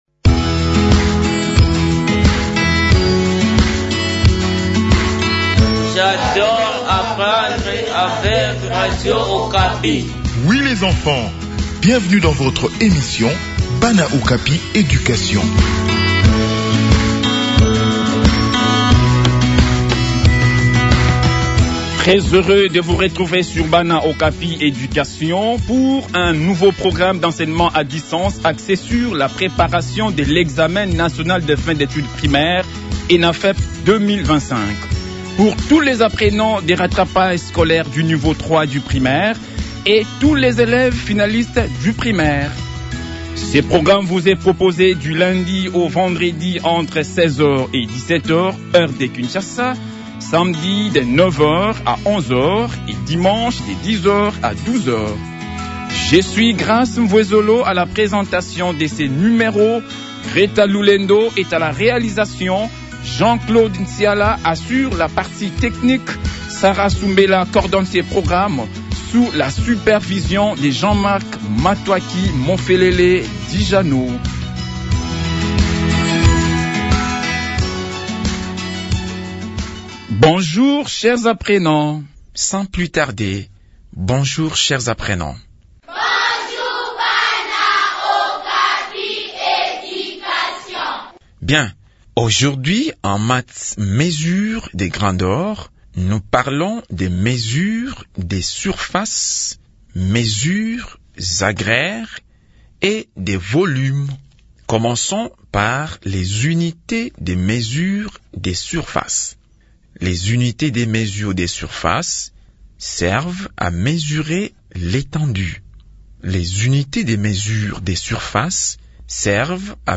Préparation aux examens nationaux : leçon de math grandeur